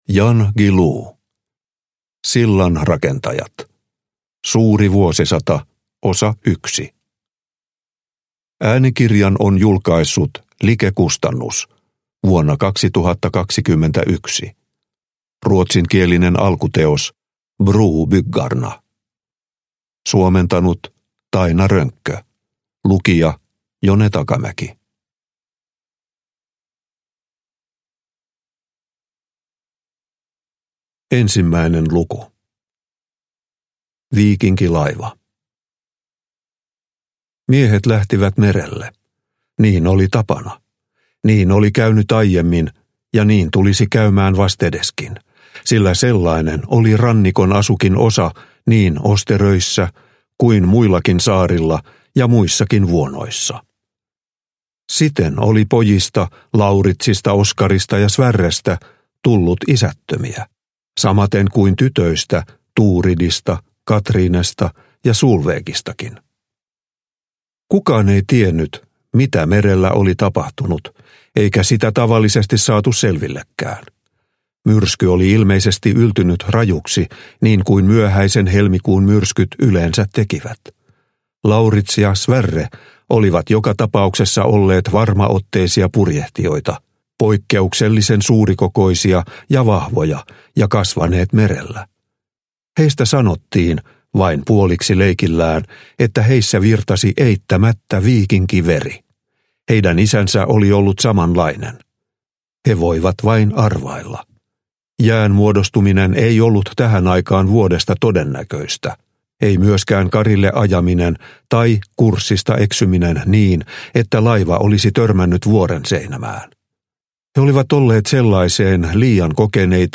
Sillanrakentajat – Ljudbok – Laddas ner